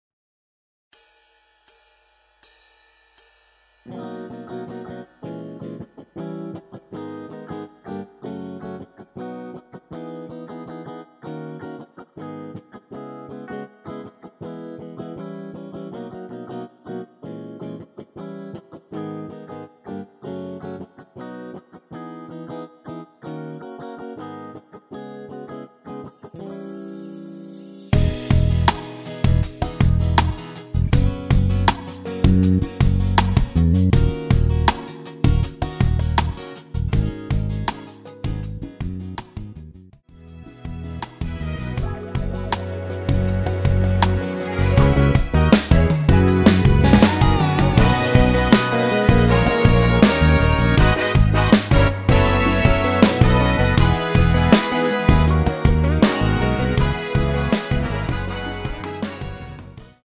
전주 없이 시작 하는 곡이라 카운트 4박 넣어 놓았습니다.(미리듣기 참조)
◈ 곡명 옆 (-1)은 반음 내림, (+1)은 반음 올림 입니다.
앞부분30초, 뒷부분30초씩 편집해서 올려 드리고 있습니다.
중간에 음이 끈어지고 다시 나오는 이유는